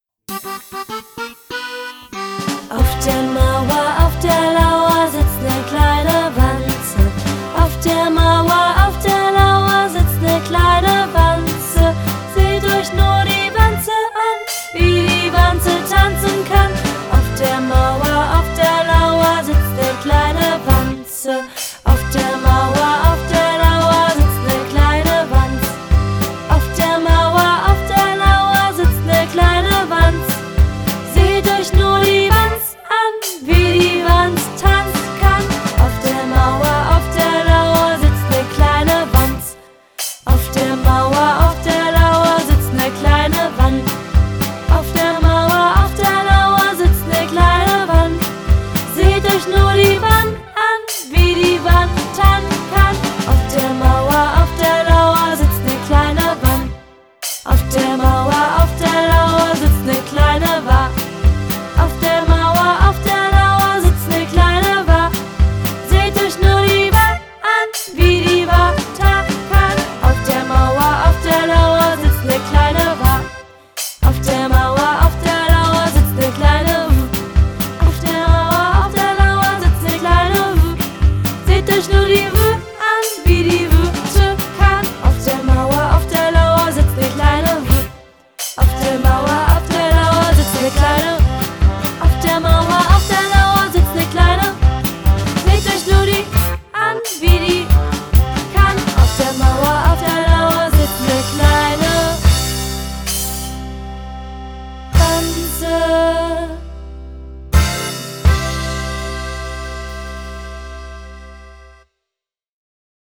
Lernlieder
ist ein klassisches Kinder-Abzählspiel und Mitmachlied